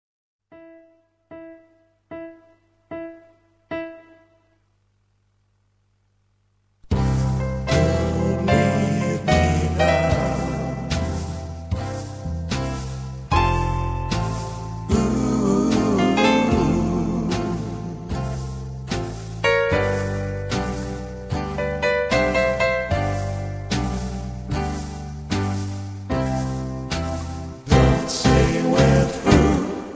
karaoke, backing tracks
rock